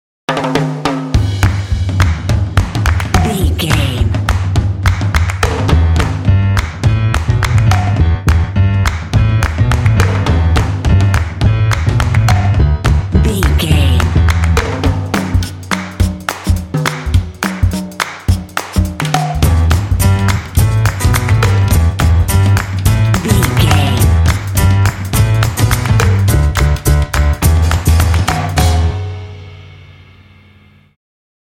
Uplifting
Aeolian/Minor
driving
energetic
lively
cheerful/happy
drums
piano
percussion